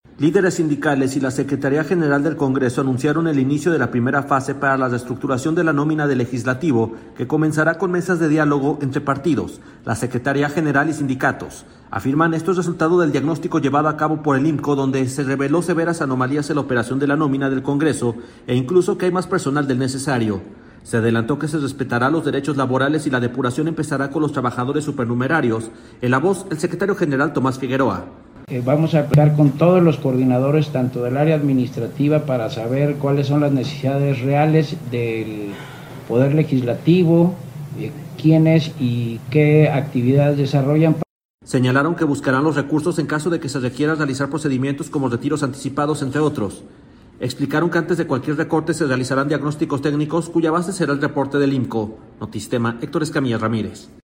Se adelantó que se respetarán los derechos laborales y la depuración empezará con los trabajadores supernumerarios. En la voz el secretario general, Tomás Figueroa.